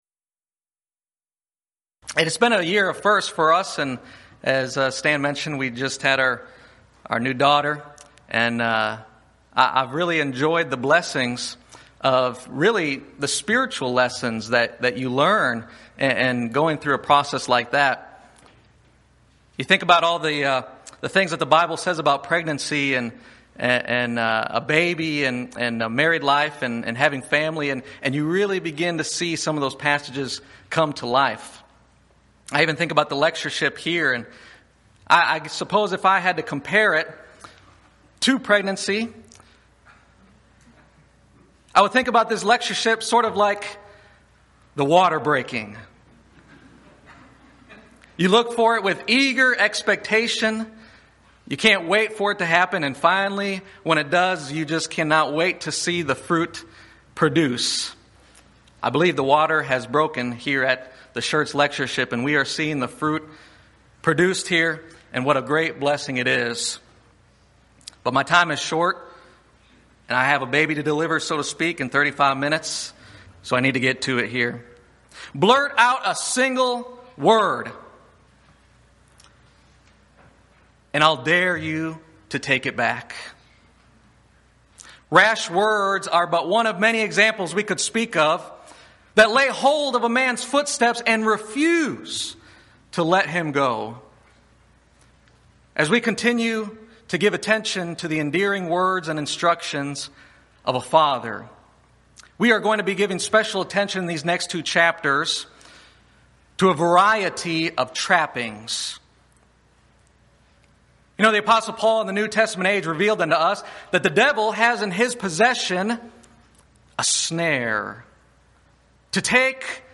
Event: 13th Annual Schertz Lectures Theme/Title: Studies in Proverbs, Ecclesiastes, & Song of Solomon